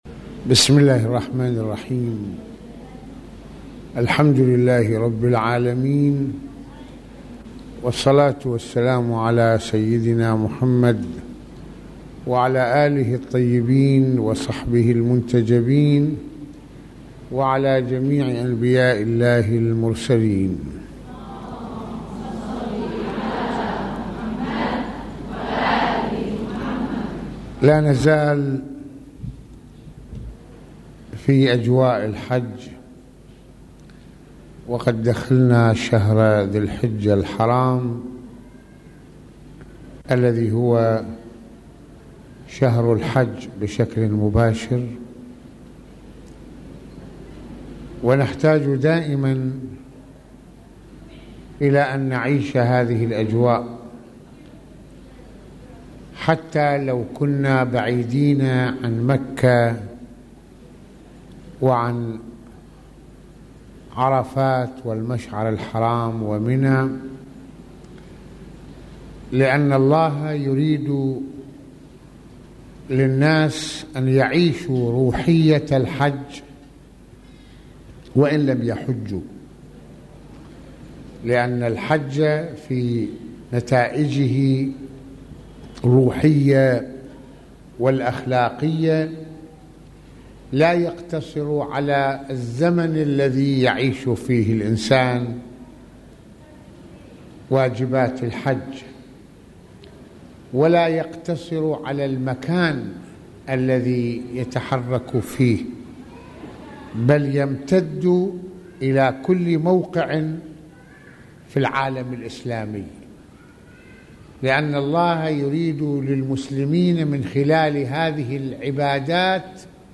- يتحدث العلامة المرجع السيّد محمّد حسين فضل الله(رض) في هذه المحاضرة عن إرادة الله للناس أن يعيشوا روحيّة الحج حتى ولو لم يحجّوا، فزمن العبادة ومنها الحج ليس محدداً فقط بزمن الحج..